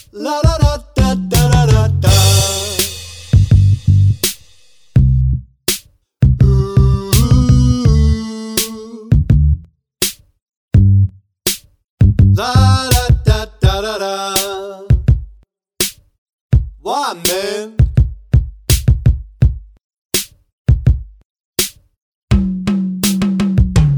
end cut R'n'B / Hip Hop 4:50 Buy £1.50